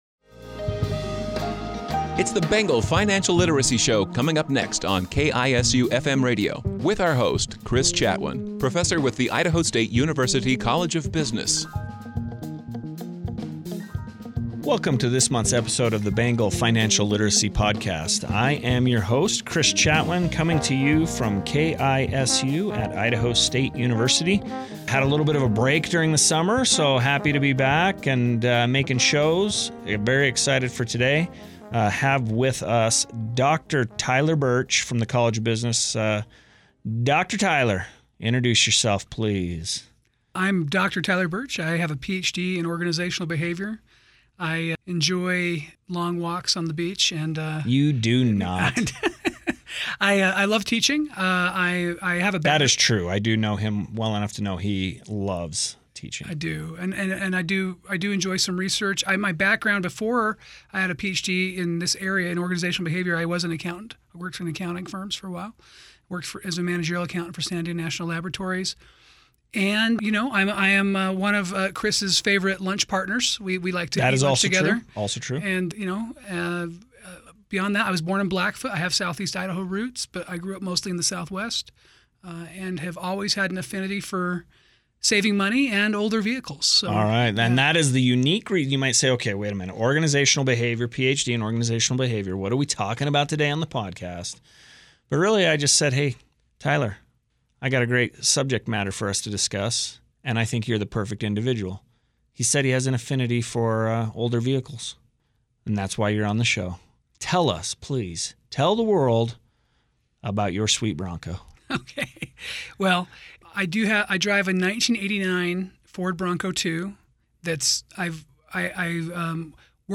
features a conversation